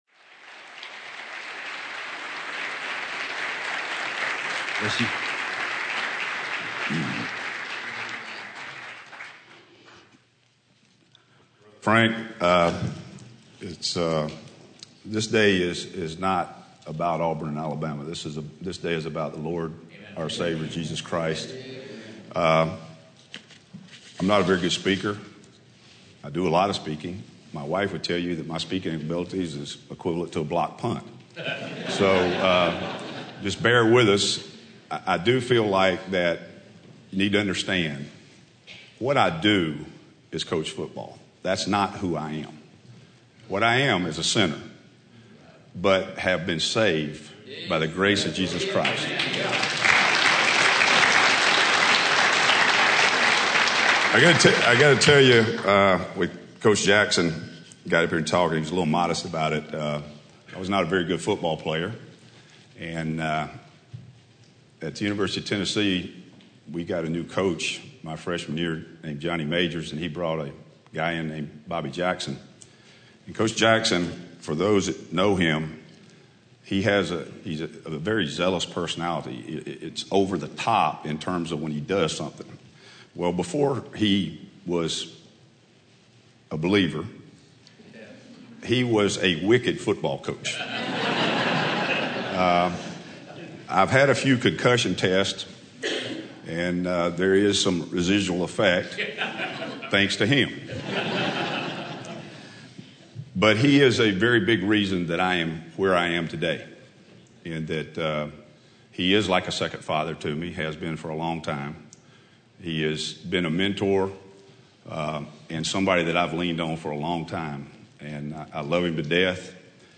Audio Sermon Video Sermon Save Audio http